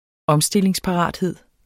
Udtale [ ˈʌmˌsdelˀeŋs- ]